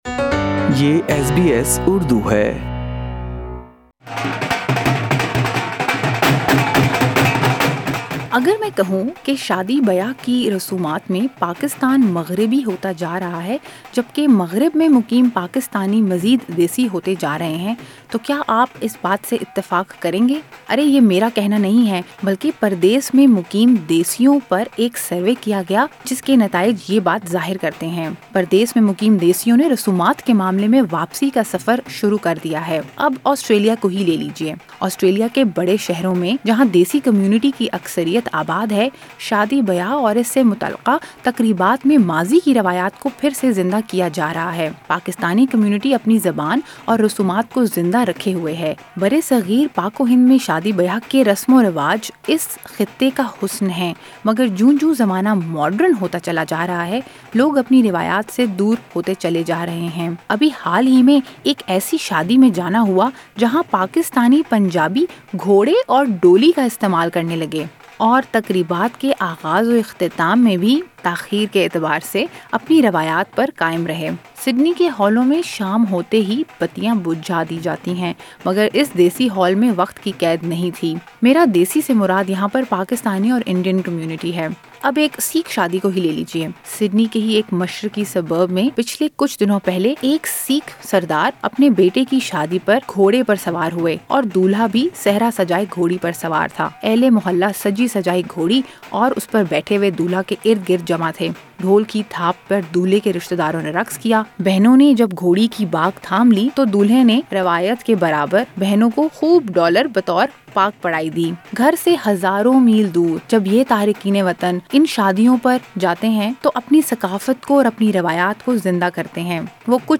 Pakistani-Australian wedding planners talk to SBS Urdu about the surprising things they have noticed at 'desi' weddings in Australia.